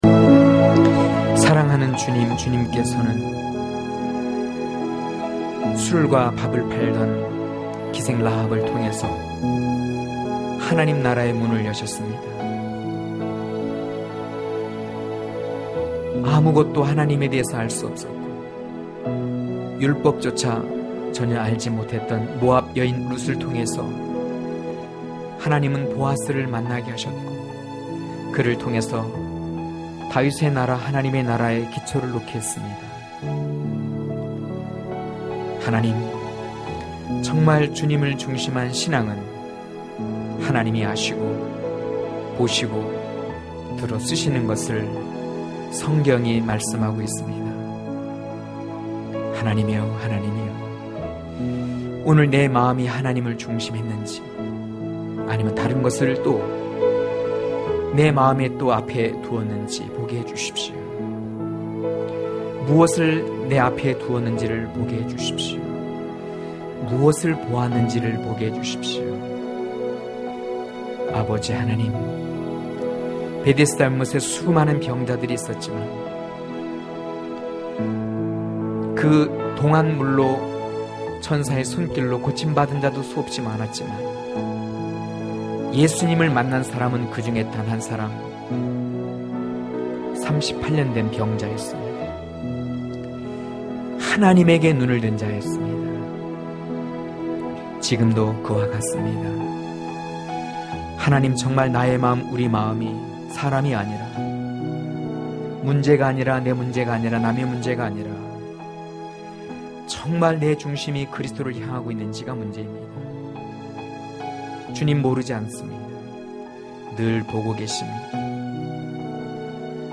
강해설교 - 09.포도원으로...(아3장1-5절)